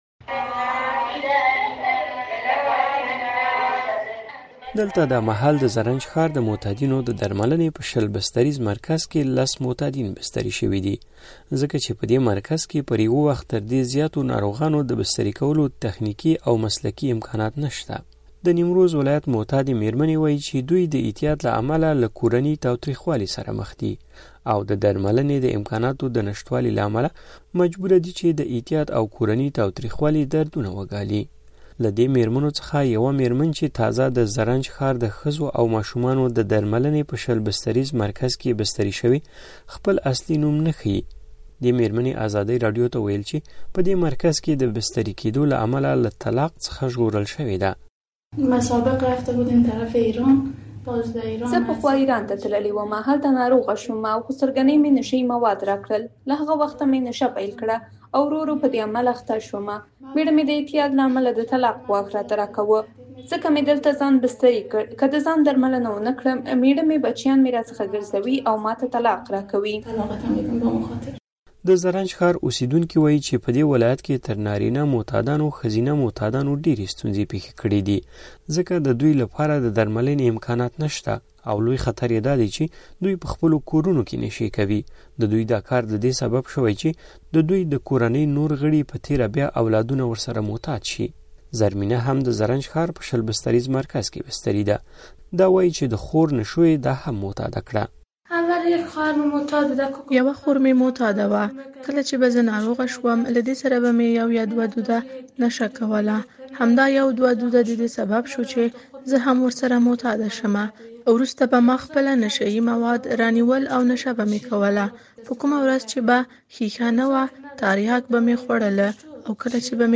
د نیمروز راپور